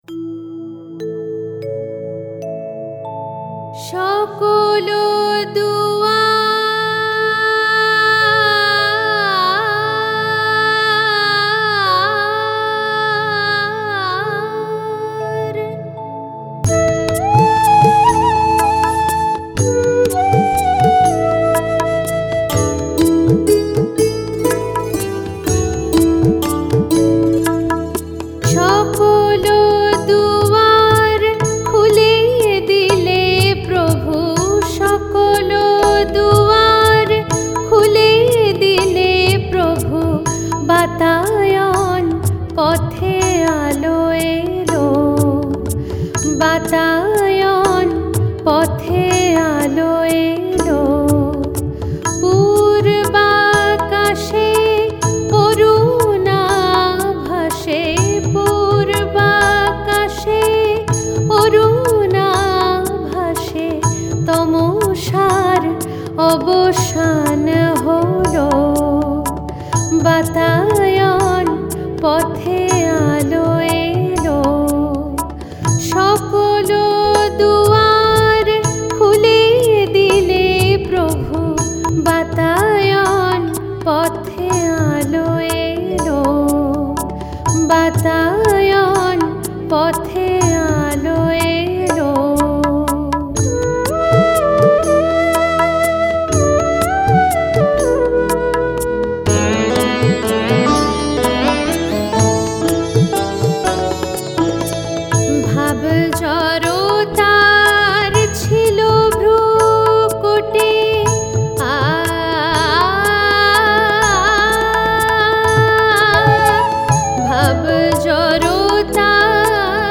Music Dadra